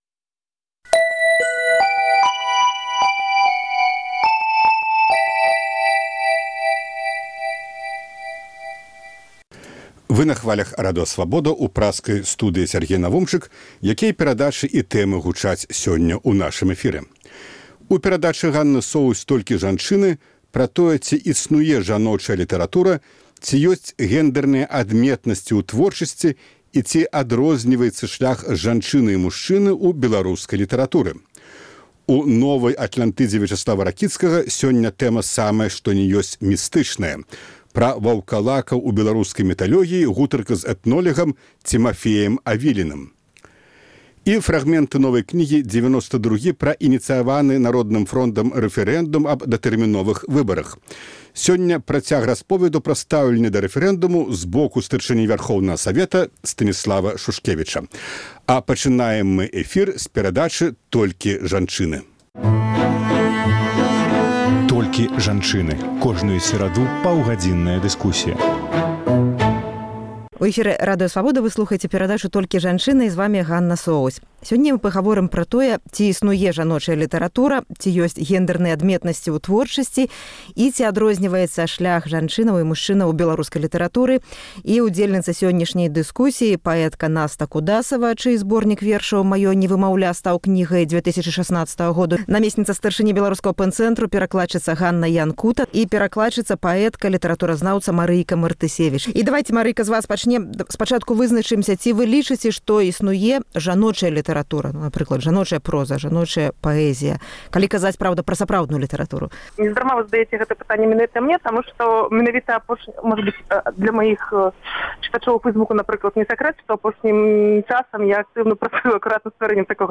У перадачы «Толькі жанчыны» абмяркоўваем, ці існуе жаночая літаратура, ці ёсьць гендэрныя адметнасьці ў творчасьці і ці адрозьніваецца шлях жанчыны і мужчыны ў беларускай літаратуры. Удзельніцы дыскусіі